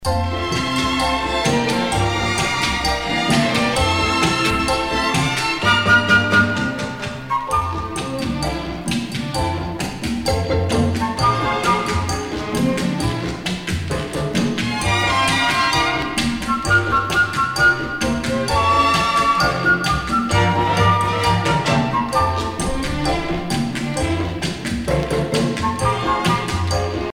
danse : cha cha cha ;
Pièce musicale éditée